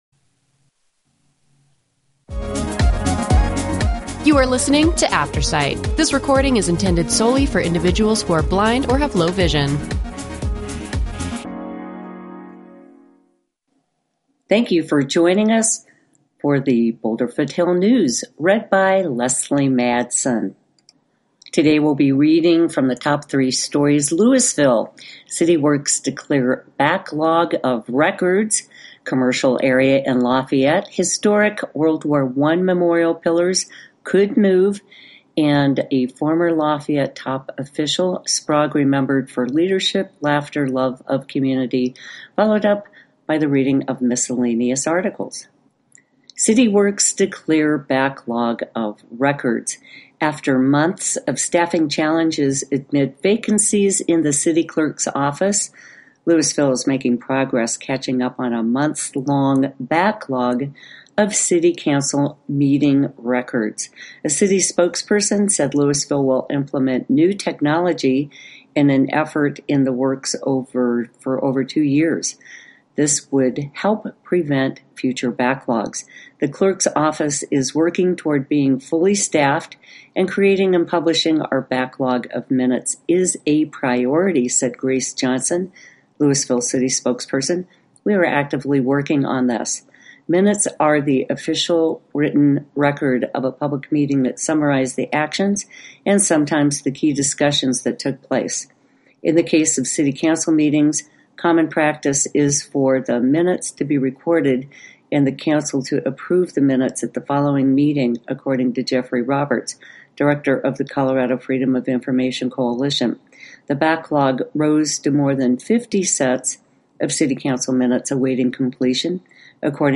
Boulder Weekly newspaper in audio.